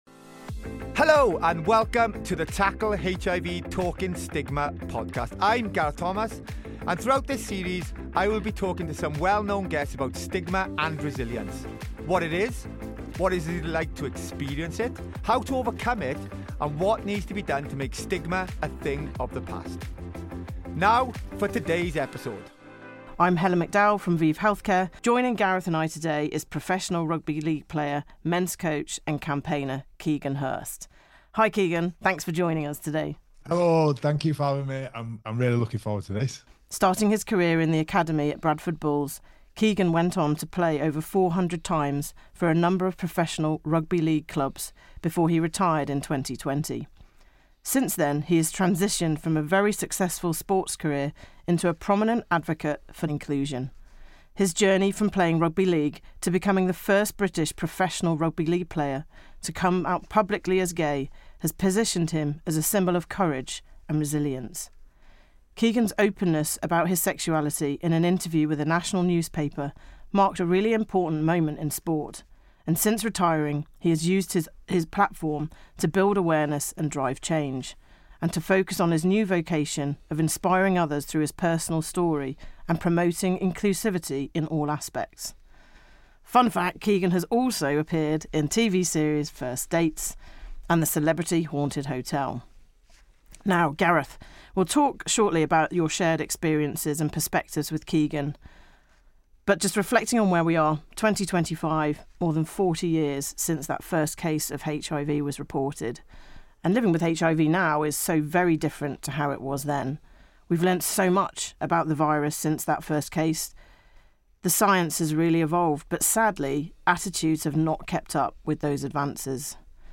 In this episode of the Talking Stigma podcast Gareth Thomas is joined by former Rugby League player Keegan Hirst. Keegan shares his story and journey regarding his sexuality, what it was like being the first openly gay rugby league player and how his experiences have shaped who he is today.